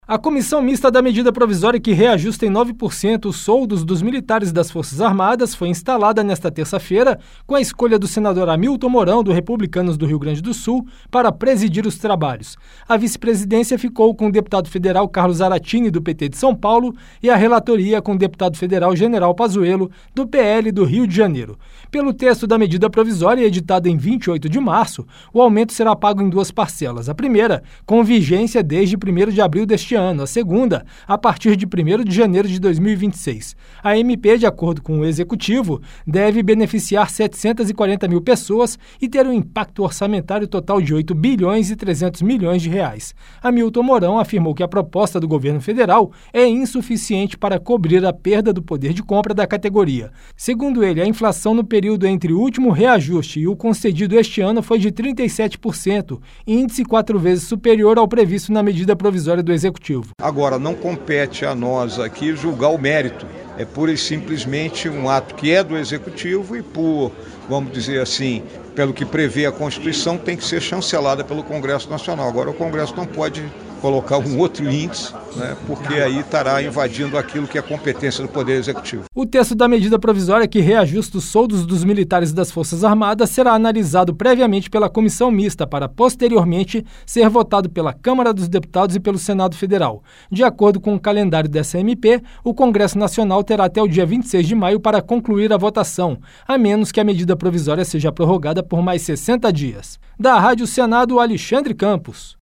A Comissão Mista que vai analisar a medida provisória que reajusta os soldos dos militares das Forças Armadas em 9% (MPV 1293/2025) foi instalada nesta terça-feira (29). Eleito presidente do colegiado, o senador Hamilton Mourão (Republicanos-RS) afirmou que a proposta do Executivo é insuficiente para repor o poder de compra da categoria.